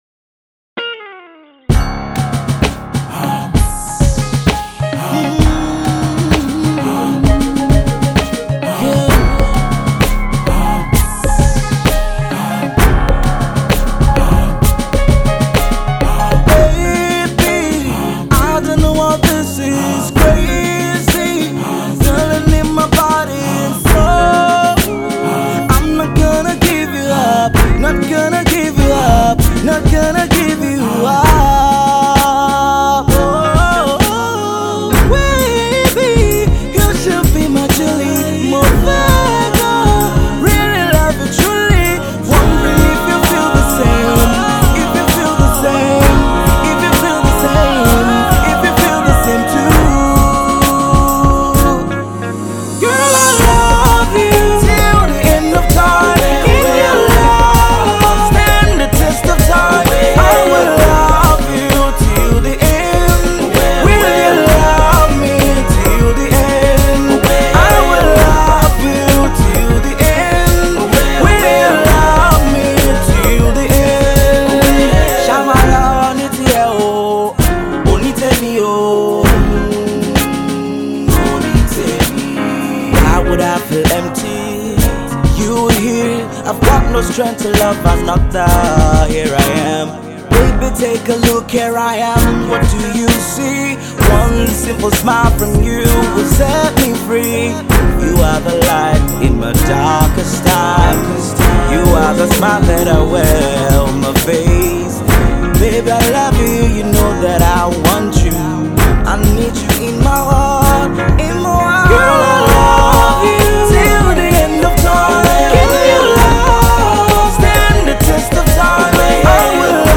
LOVE SONG OF LIFE